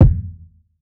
Kicks
Told You Kick.wav